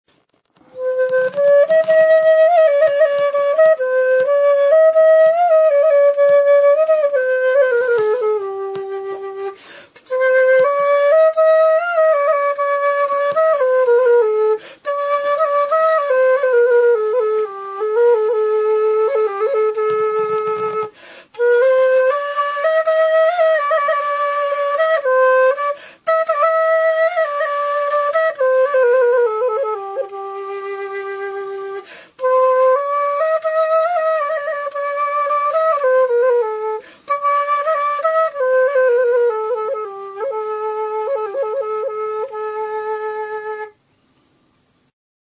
FUVOLA; FLÓTA | Soinuenea Herri Musikaren Txokoa
Zurezko zeharkako flauta da. Doinu aldaketarako sei zulo ditu aurrealdean.
Aérophones -> Flûtes -> Traversière